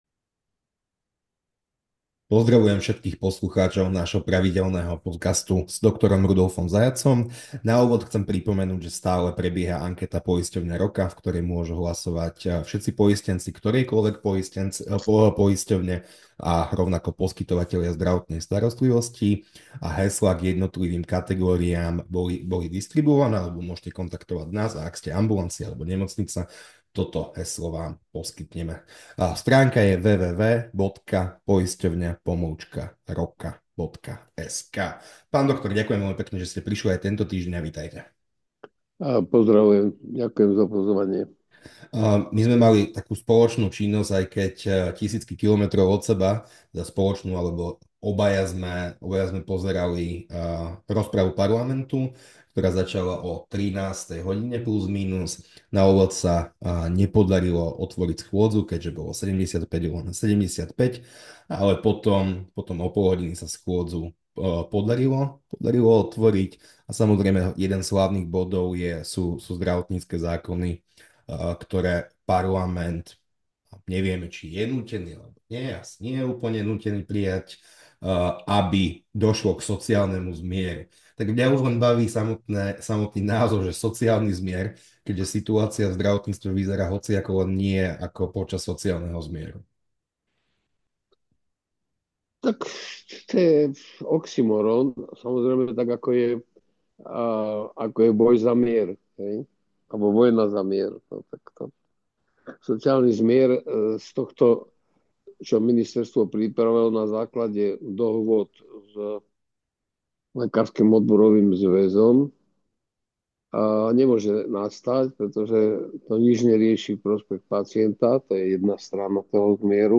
S exministrom zdravotníctva Rudolfom Zajacom hovoríme v podcaste o parlamentnej rozprave.